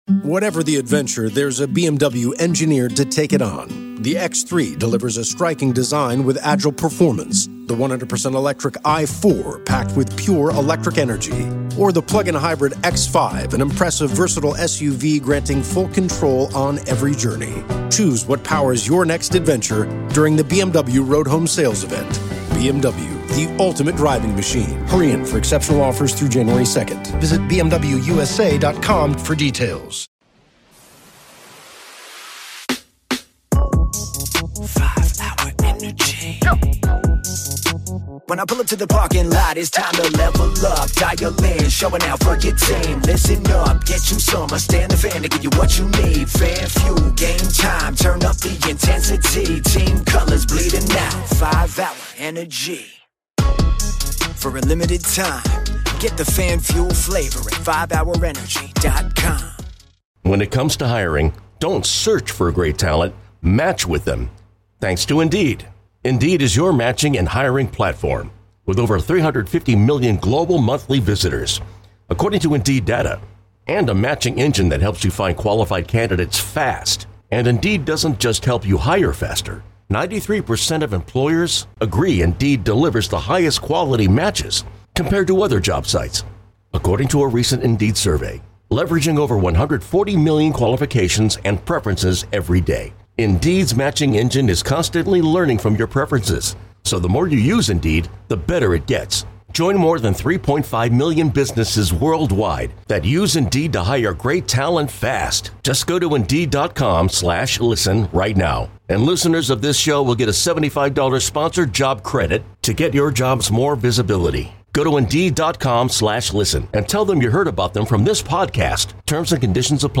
From 'The TK Show' (subscribe here): A short interview with Warriors owner Joe Lacob on topics ranging from the trade for Dennis Schroder, the urgency of this moment, Jonathan Kuminga, the Valkryies launch and the upcoming All-Star Game at Chase Center.